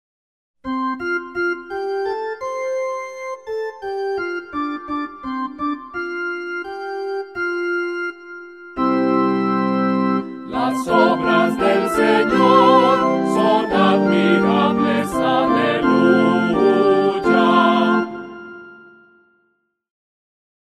SALMO RESPONSORIAL Del salmo 65 R. Las obras del Señor son admirables.